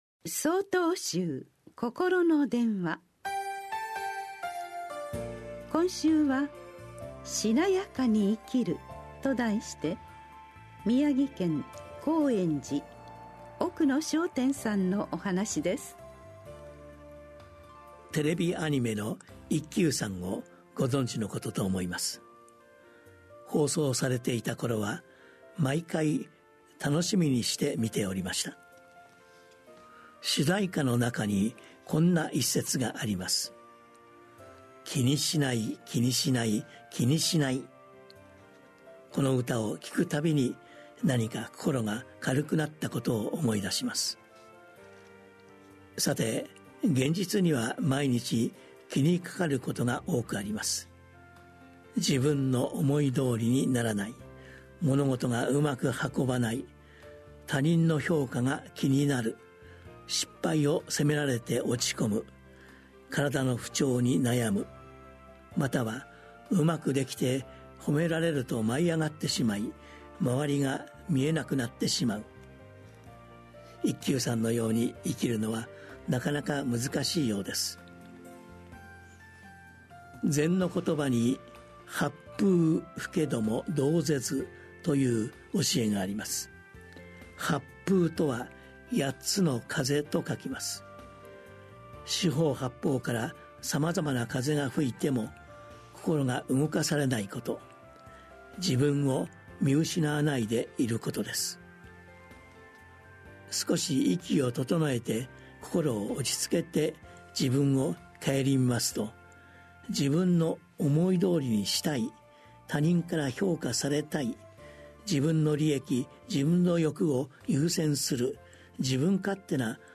曹洞宗では毎週、わかりやすい仏教のお話（法話）を、電話と音声やポッドキャストにて配信しています。